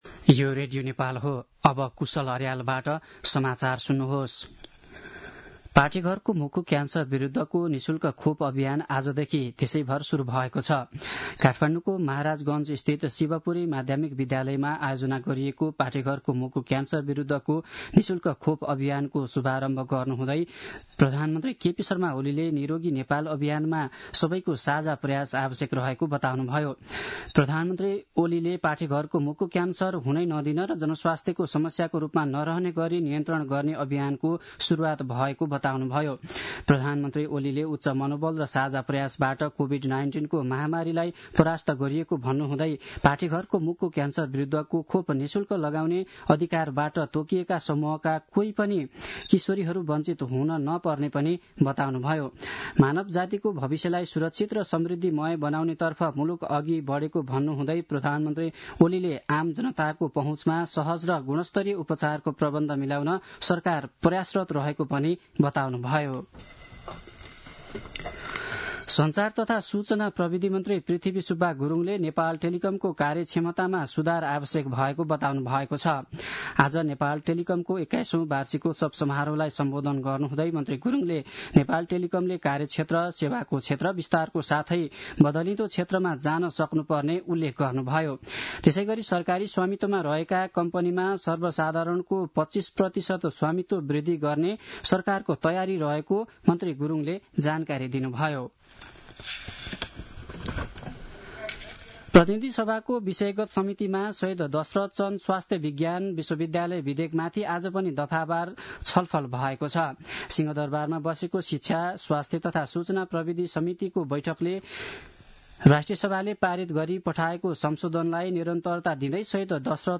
साँझ ५ बजेको नेपाली समाचार : २३ माघ , २०८१